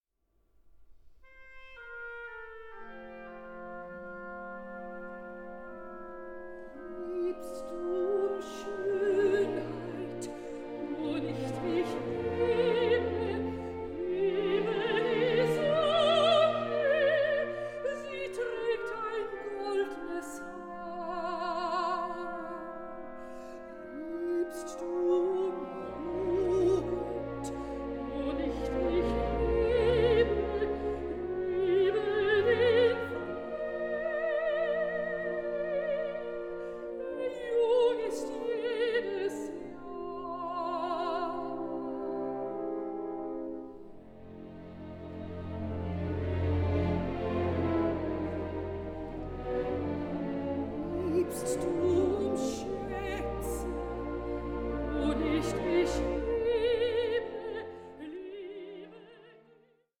mezzo-soprano
orchestral song cycles
Richly lyrical, poignant and soul searching